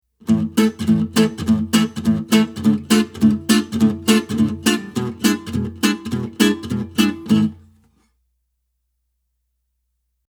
Treble strings 2 and 4 Example: